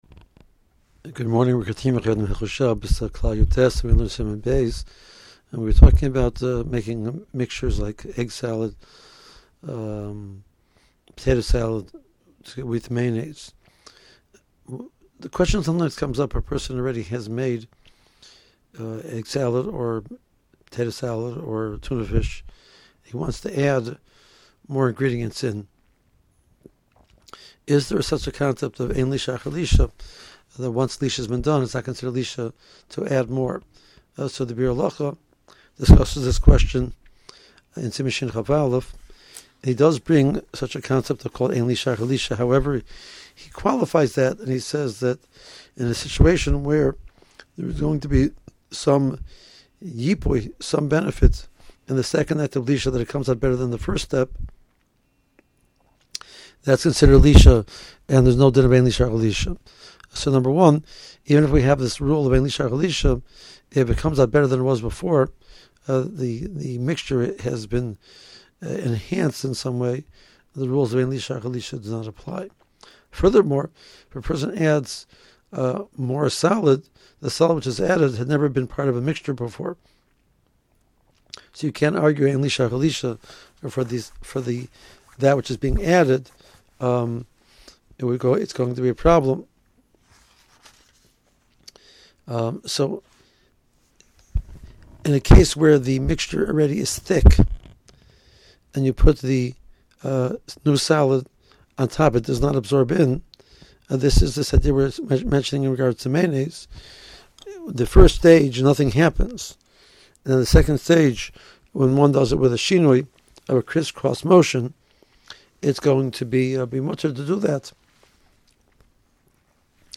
• 5 Min. Audio Shiur Including Contemporary Poskim